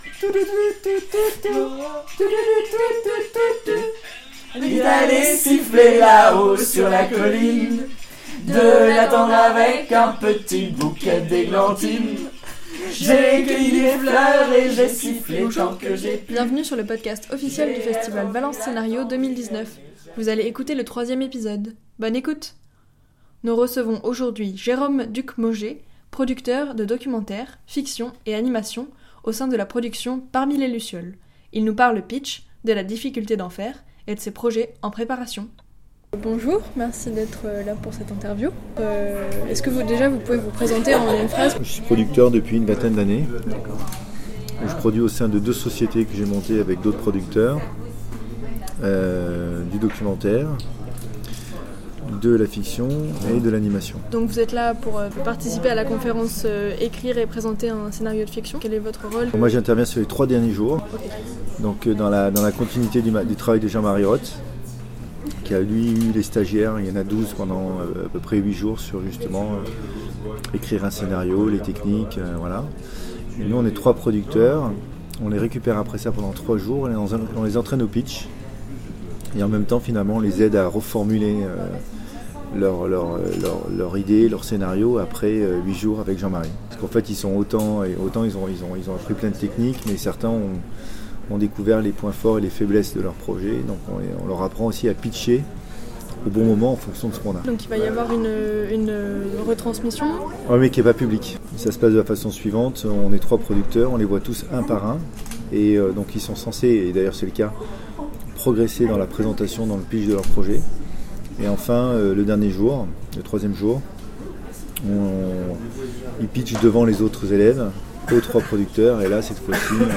Interview
à l’hôtel de France.